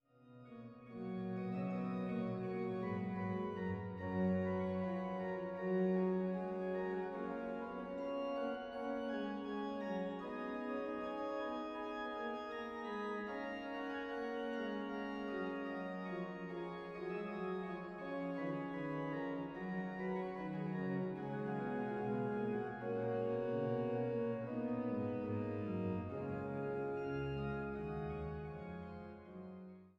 Woehl-Orgel in der Thomaskirche zu Leipzig